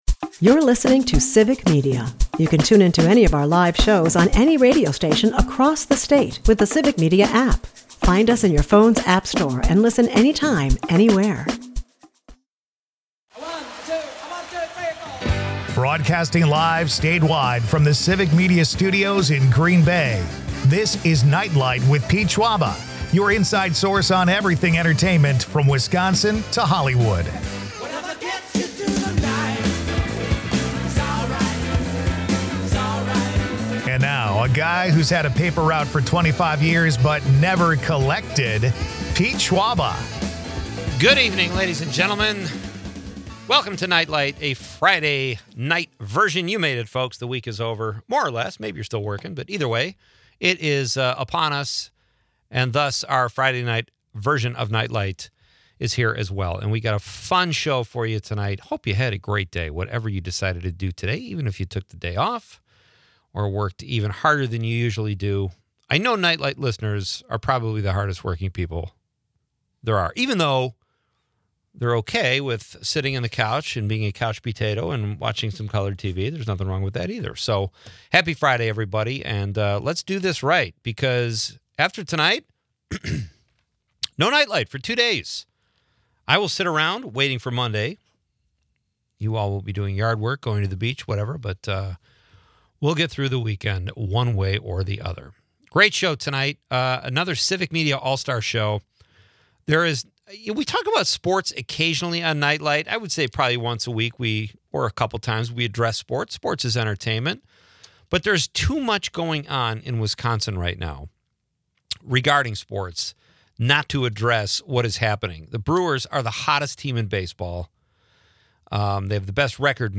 Listeners join the hilarity by concocting disgusting smoothies to rival Heinz's ketchup and fruit blend, ranging from blob fish to pickled pig's feet. Plus, Eddie Murphy's candidness about his film flops, including one with a 0% Rotten Tomatoes score.